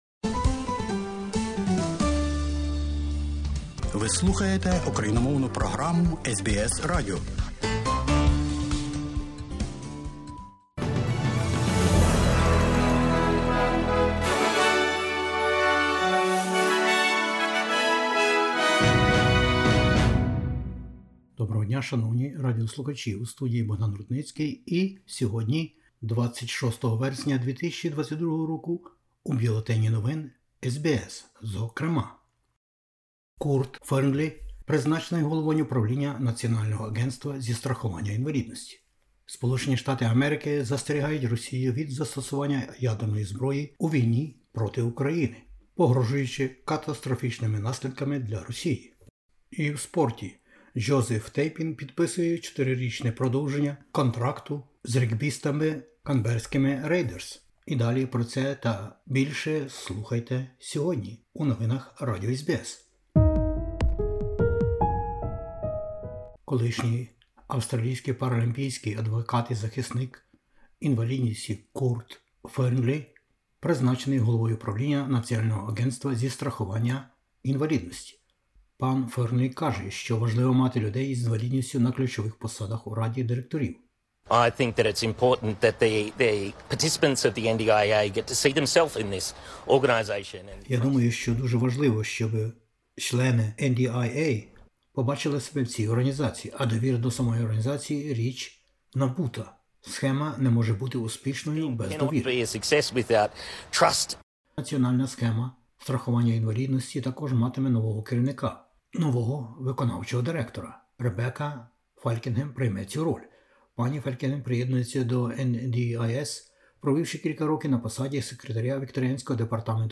Бюлетень SBS новин - 26/09/2022